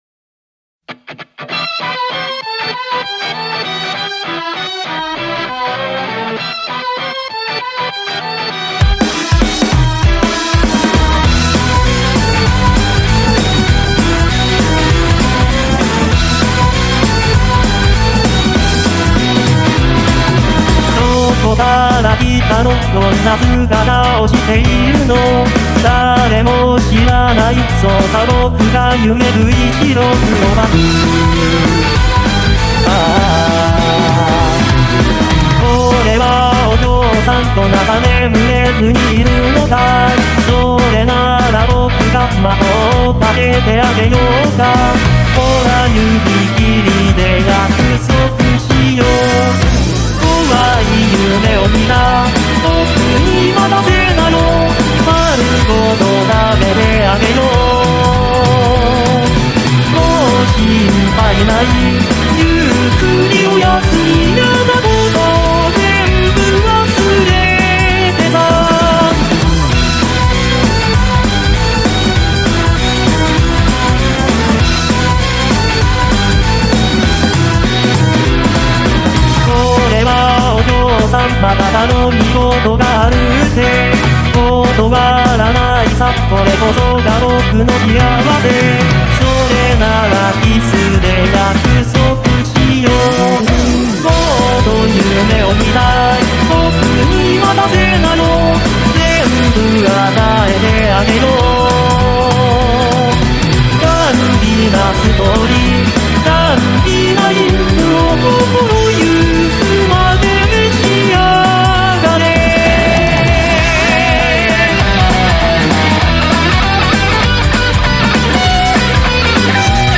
vocaloid